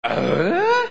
Tim the Tool Man Taylor Grunt